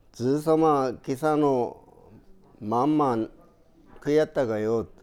Aizu Dialect Database
Type: Statement
Final intonation: Falling
Location: Showamura/昭和村
Sex: Male